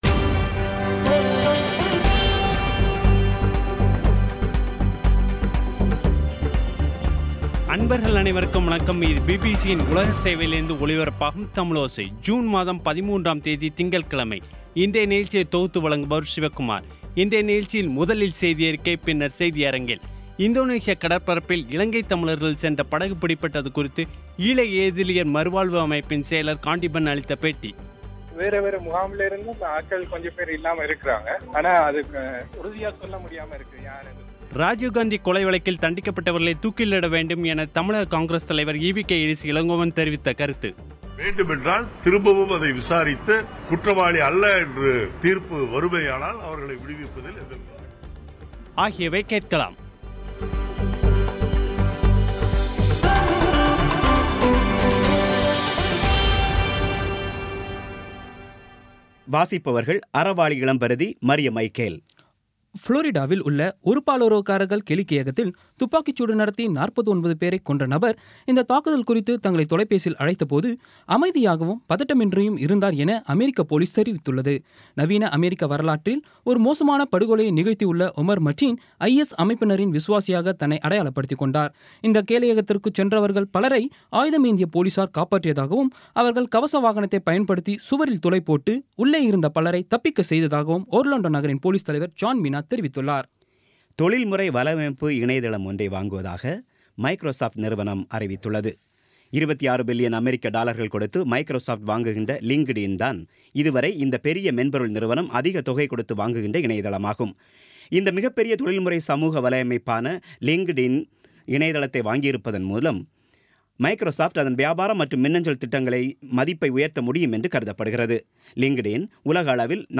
இன்றைய நிகழ்ச்சியில் முதலில் செய்தியறிக்கை பின்னர் செய்தியரங்கில்